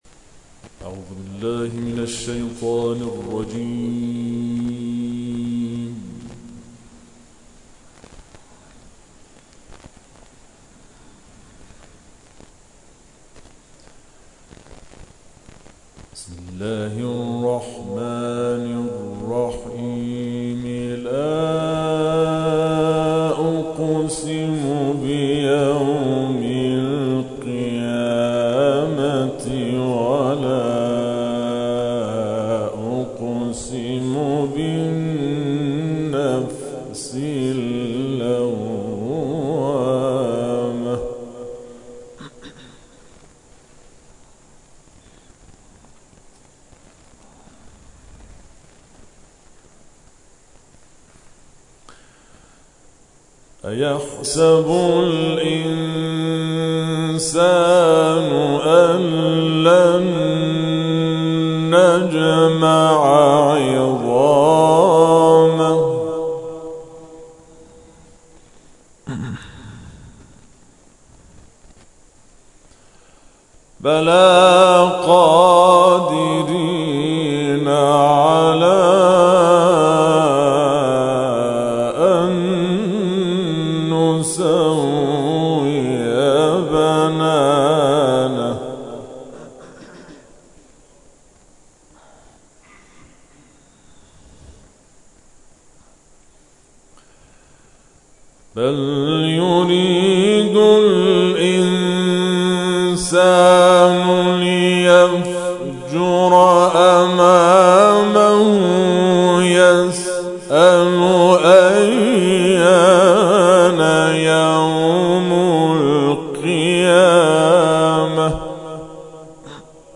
گروه جلسات و محافل: ششمین کرسی قرائت و تفسیر قرآن در مسجد نور